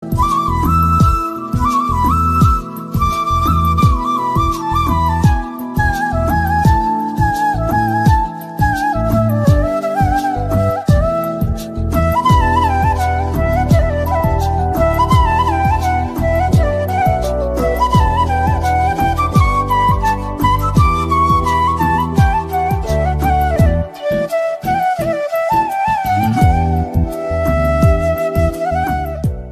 Bansuri Ringtones Flute Ringtones Instrumental Ringtones